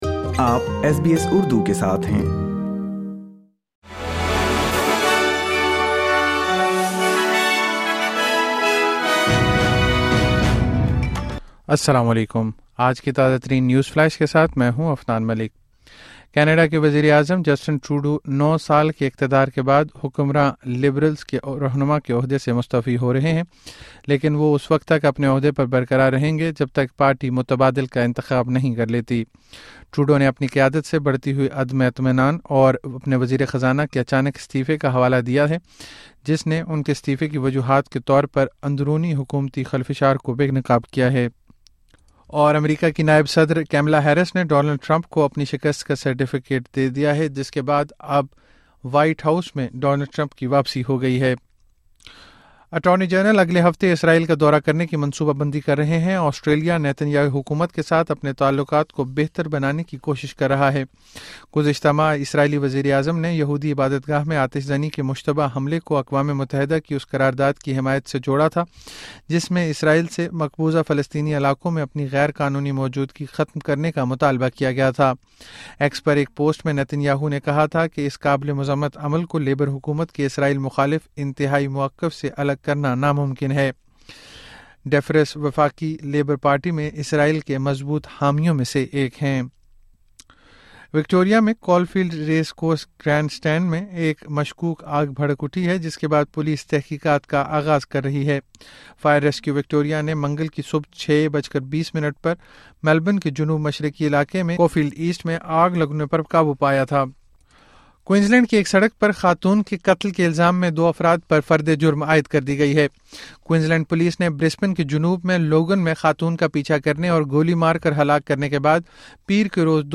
اردو نیوز فلیش: 07 جنوری 2025